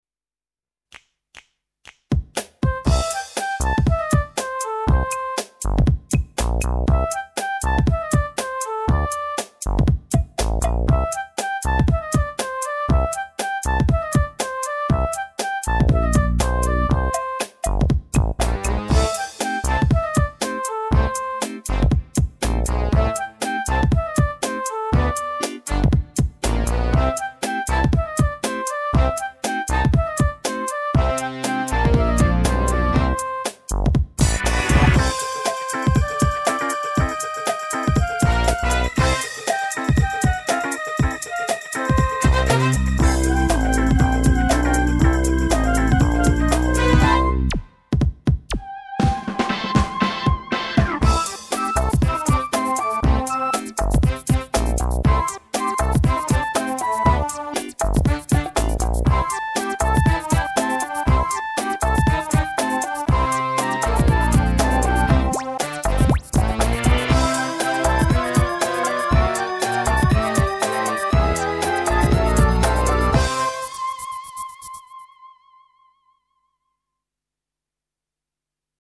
Instrumentation:Solo Flute with mp3 backing tracks
Great percussion backing.